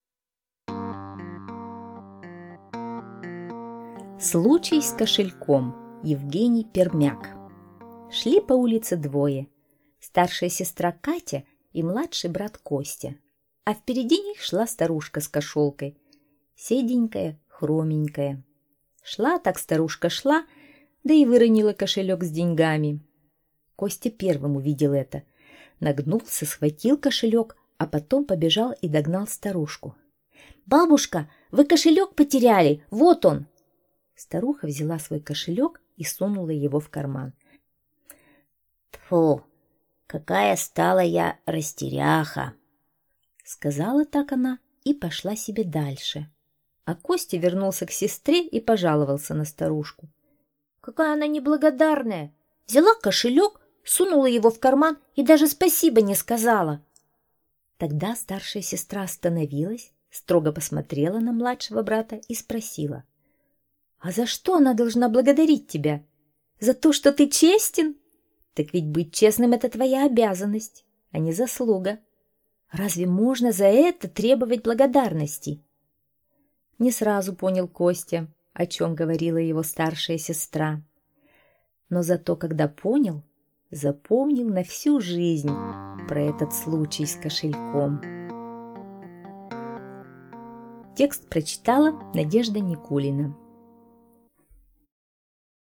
Случай с кошельком - аудио рассказ Пермяка Е.А. Шли по улице старшая сестра Катя и младший брат Костя.